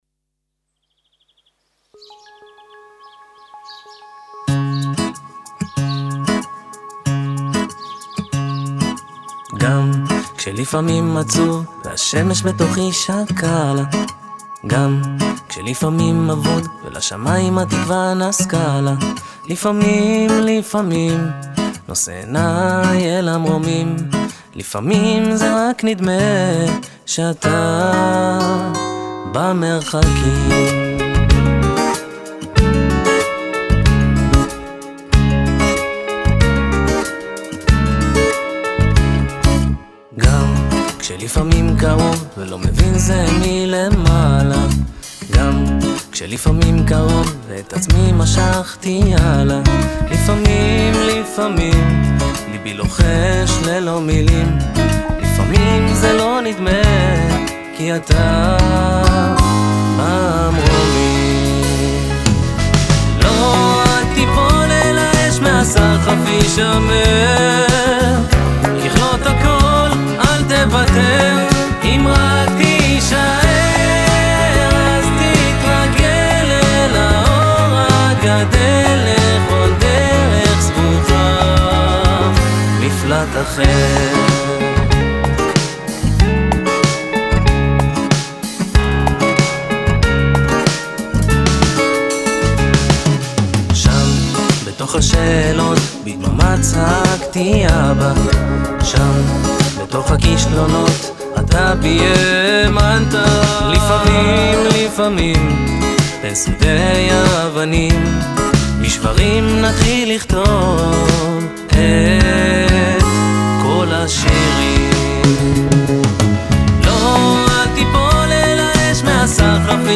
מדובר בלהיט קיץ קליל, קצבי, אופטימי ומלא בתקווה